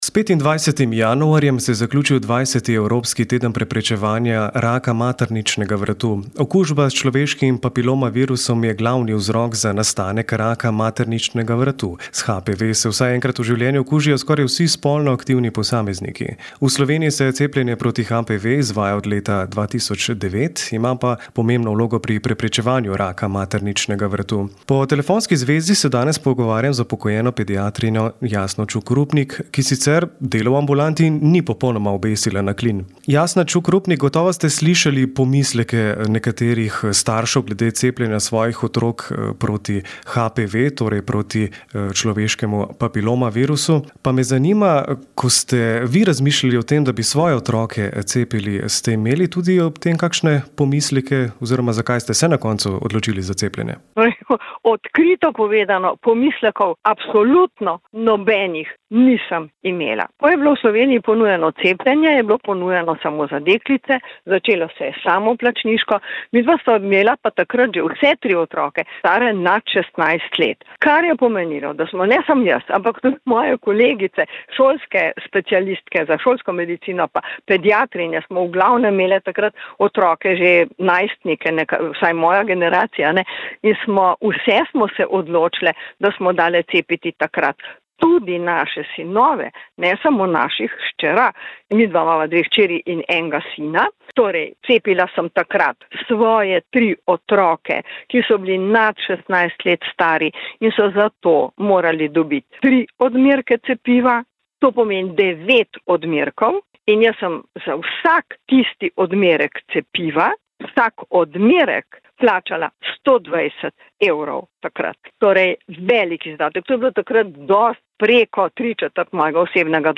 Pogovor lahko poslušate v zvočnem zapisu.